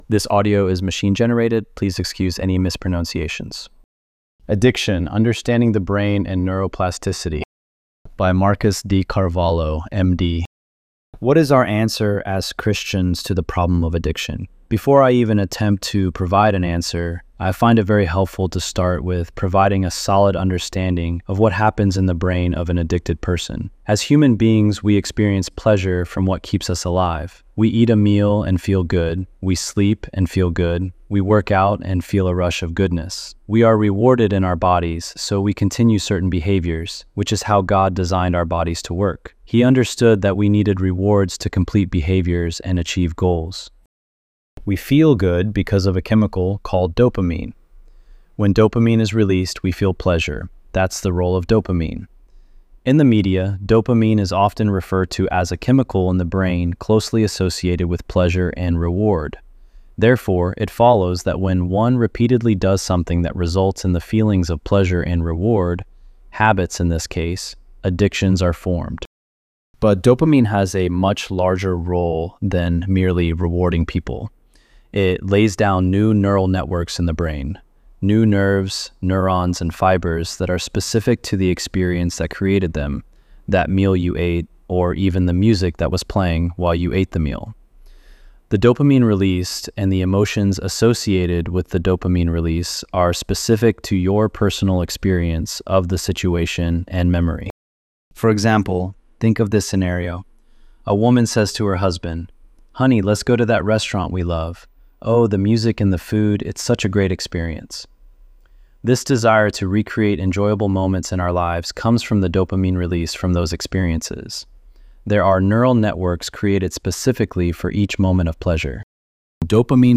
ElevenLabs_4_23.mp3